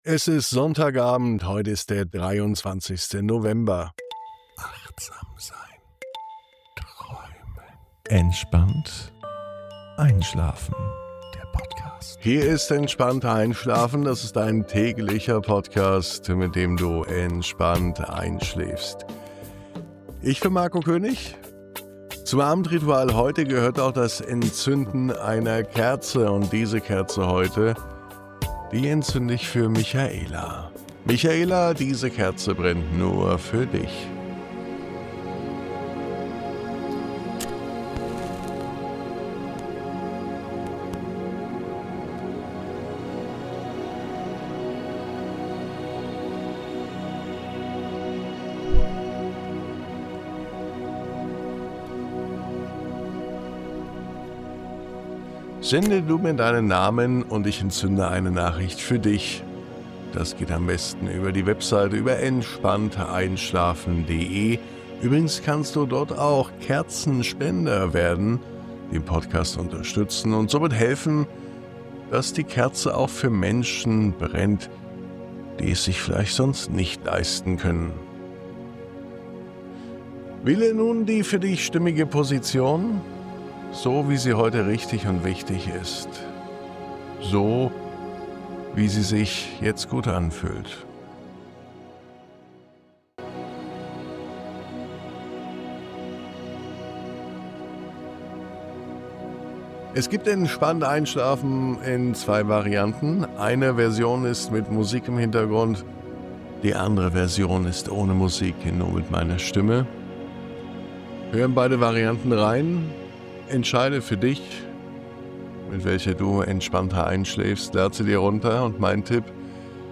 1123_MUSIK.mp3